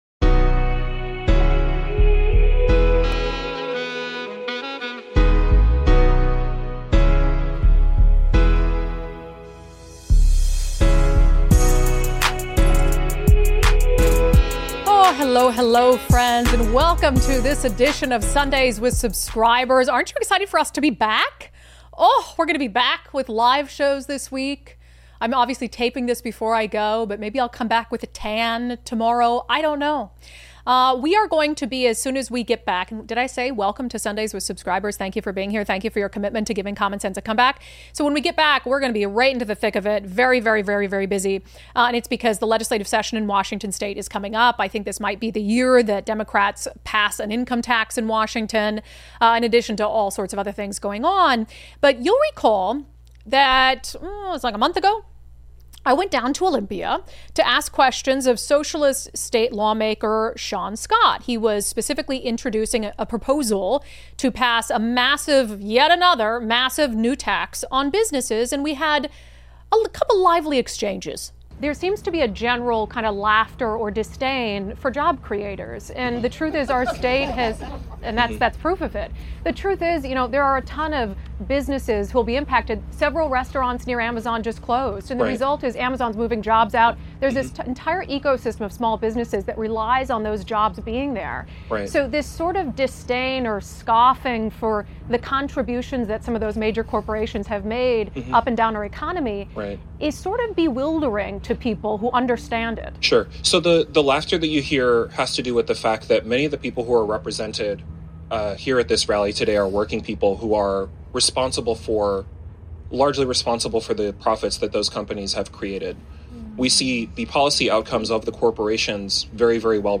Today: Washington State Rep. Shaun Scott, a socialist who caucuses with Democrats, joins us to discuss and debate taxes, spending, and the role of government. Plus: Can he name an example of where socialism has been a success? Editorial note: This interview was taped before the arrest of Venezuela's socialist dictator, Nicolas Maduro.